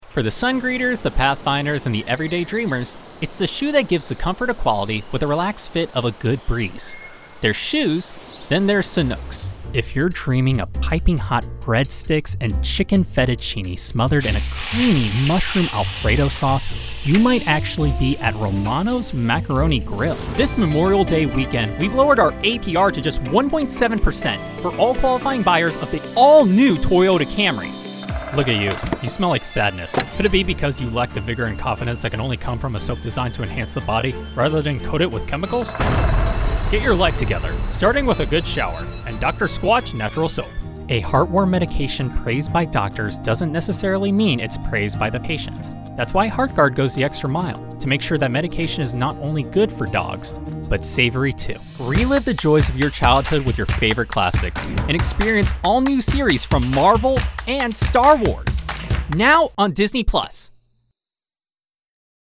Clients can expect an exceptional performance from a soundproofed home studio with Source-Connect alongside Adobe Audition and Reaper as DAWs of choice.
Location: North Hollywood, Los Angeles, CA, USA Languages: english Accents: russian | character southern us | character standard british | character standard us | natural Voice Filters: VOICEOVER GENRE ANIMATION 🎬 COMMERCIAL 💸